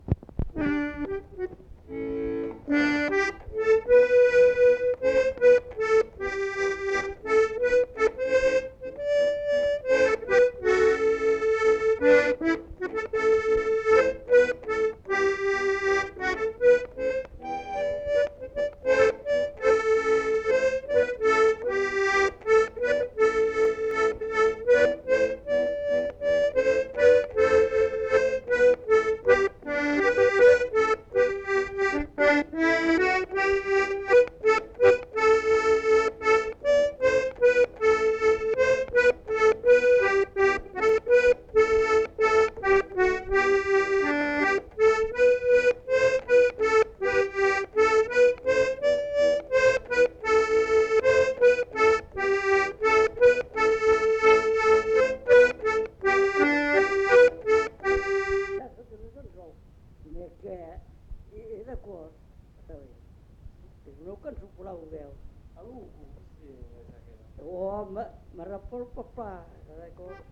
Genre : morceau instrumental
Instrument de musique : accordéon diatonique
Danse : valse
Notes consultables : Joue avec hésitations.
Ecouter-voir : archives sonores en ligne